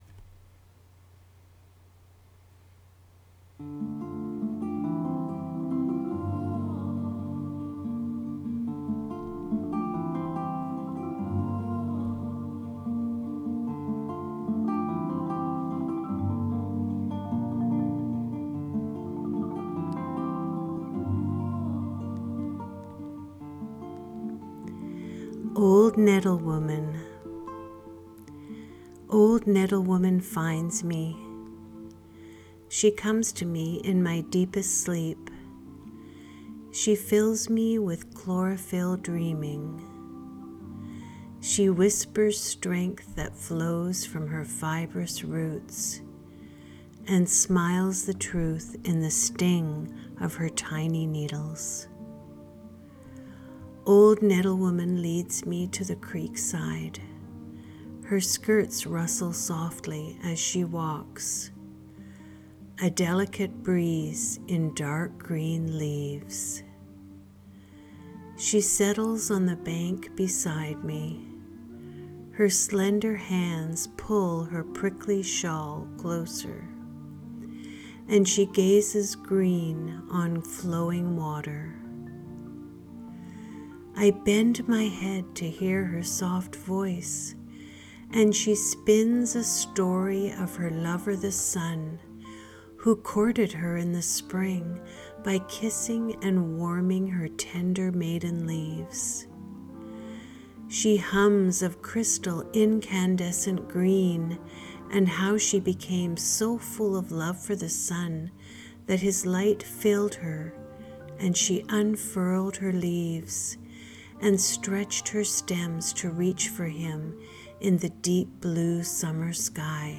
Music accompanying reading: To Hugo by Clogs from the album: The Creatures in the Garden of Lady Walton